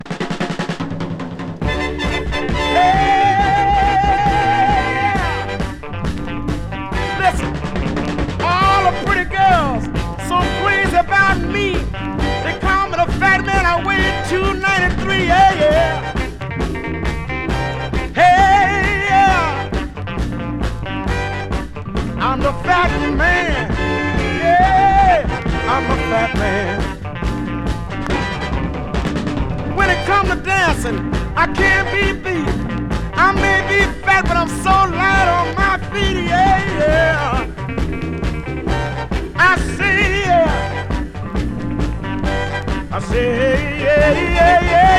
Rhythm & Blues, Funk, Soul　USA　12inchレコード　33rpm　Mono